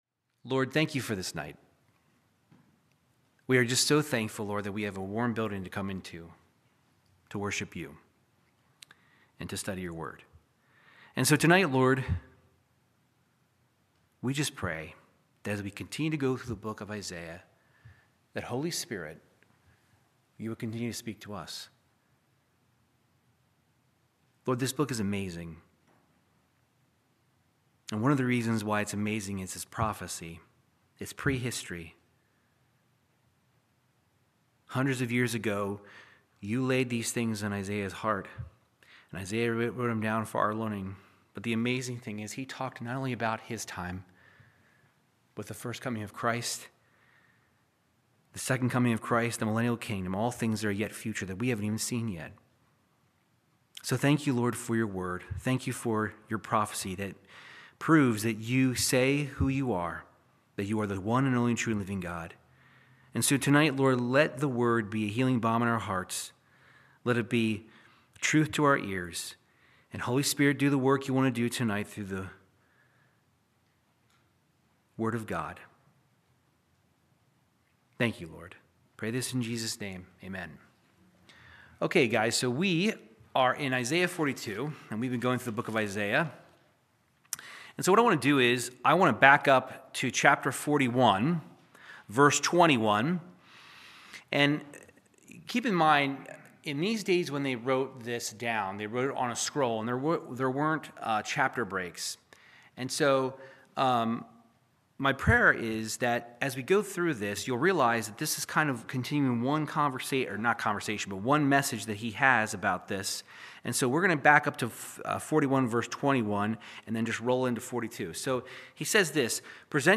Verse by verse Bible teaching through the book of Isaiah chapter 42